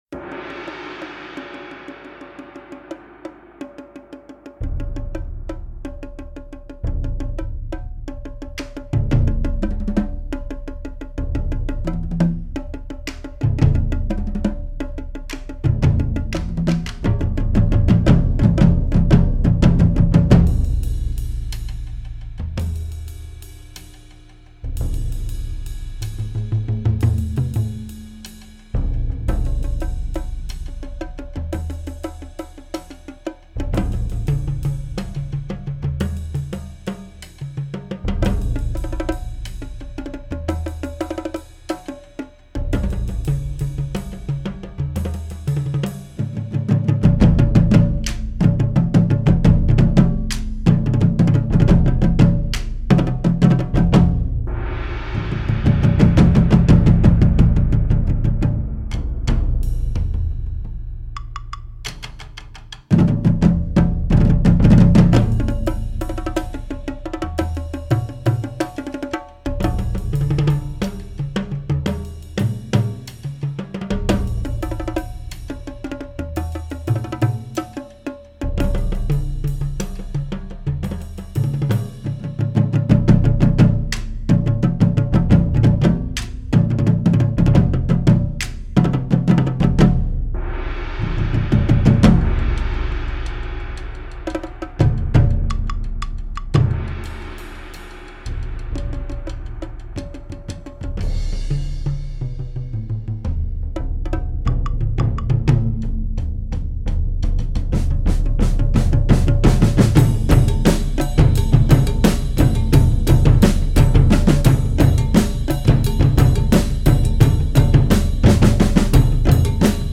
Voicing: Percussion Septet